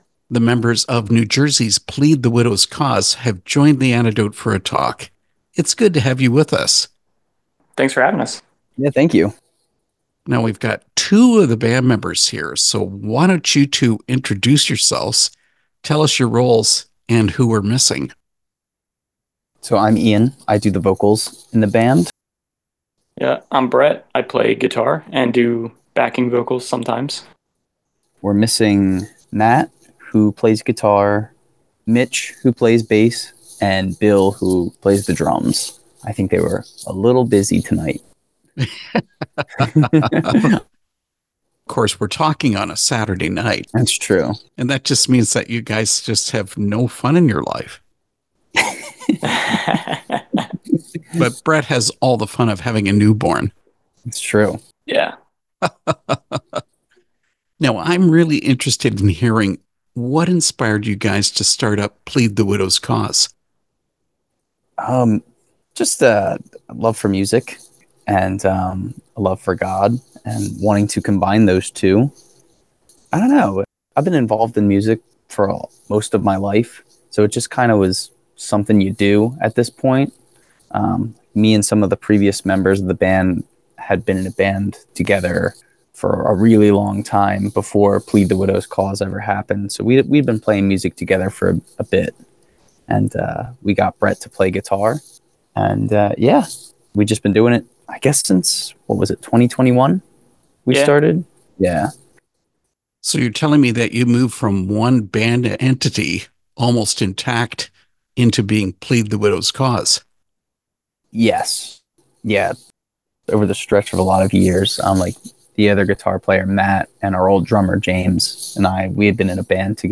Interview with Plead The Widow’s Cause
plead-the-widow's-cause-interview.mp3